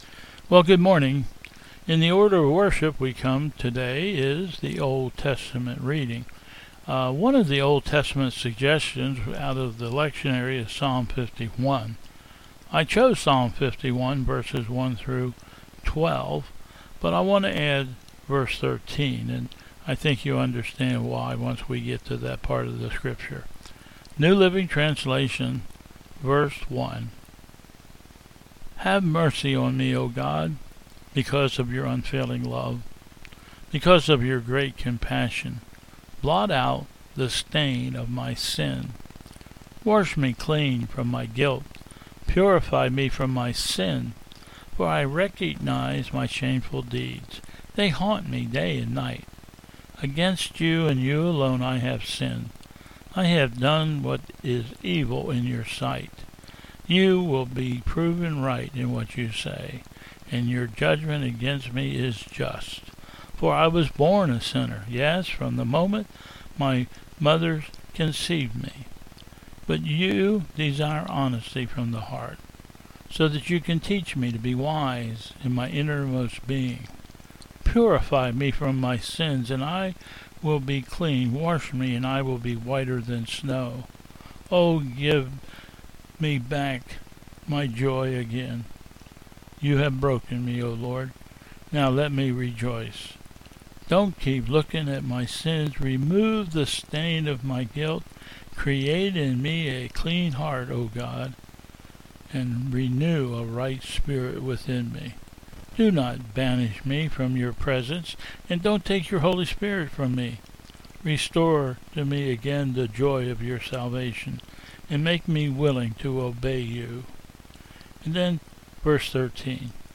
Bethel 03/21/21 Service
Processional